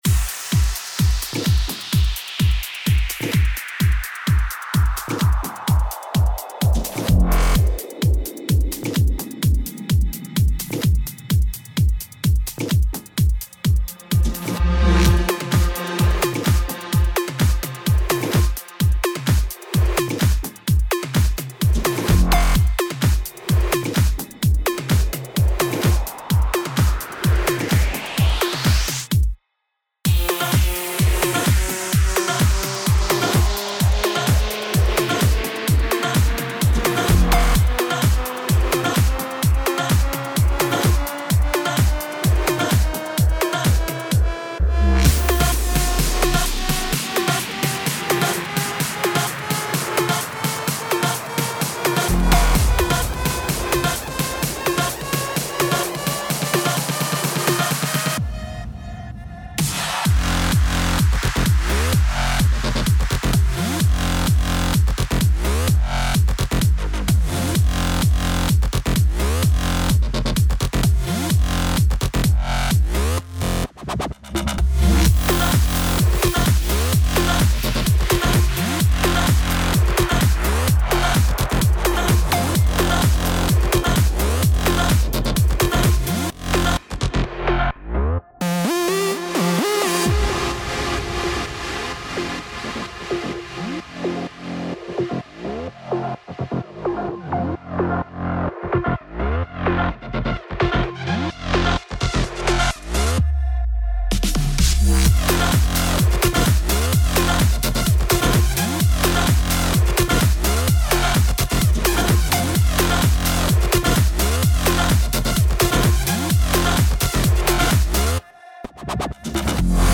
Прошу оценить сведение [Electro-trance]
Мастеринг не делался.